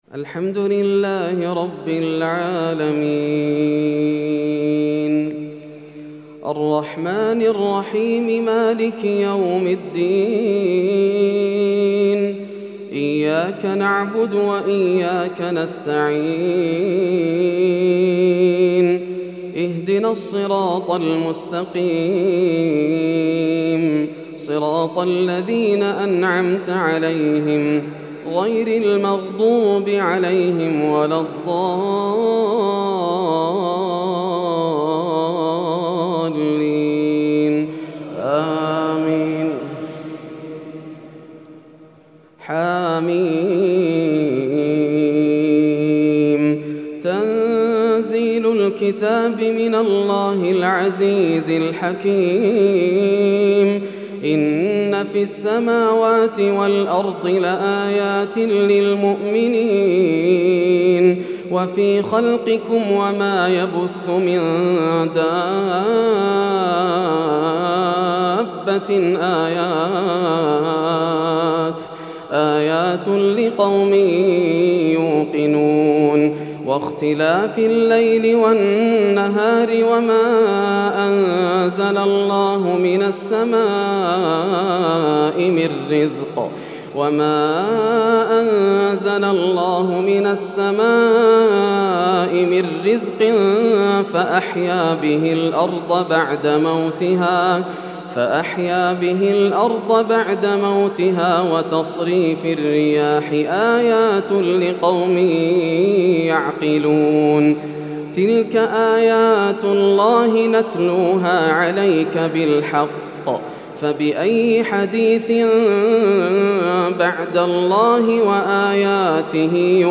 ليلة 27 رمضان 1431 سورة الجاثية حتى سورة الرحمن > الليالي الكاملة > رمضان 1431هـ > التراويح - تلاوات ياسر الدوسري